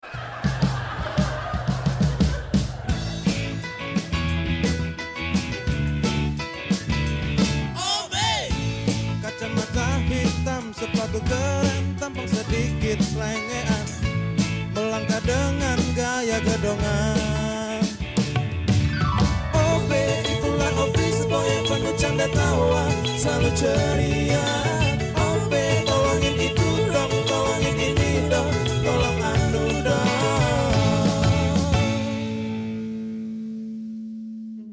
Opening Theme Song